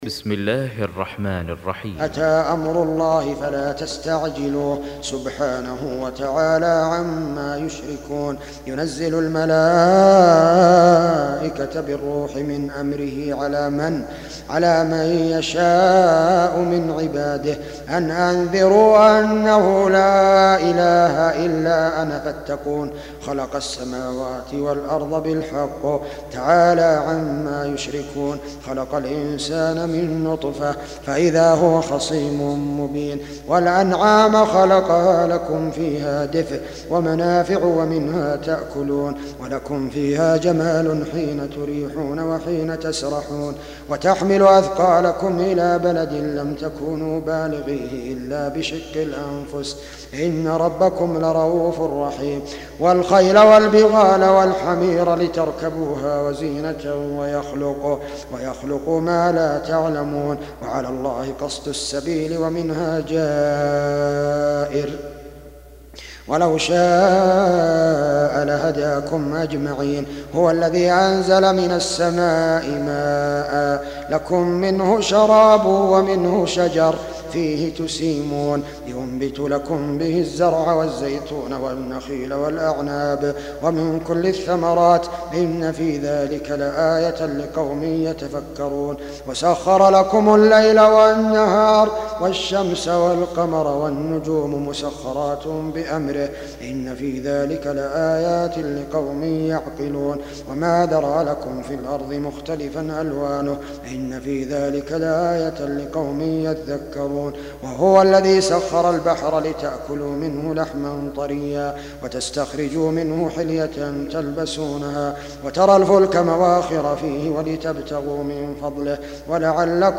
Surah Repeating تكرار السورة Download Surah حمّل السورة Reciting Murattalah Audio for 16. Surah An-Nahl سورة النحل N.B *Surah Includes Al-Basmalah Reciters Sequents تتابع التلاوات Reciters Repeats تكرار التلاوات